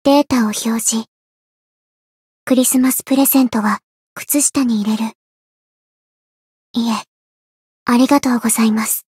灵魂潮汐-阿卡赛特-圣诞节（送礼语音）.ogg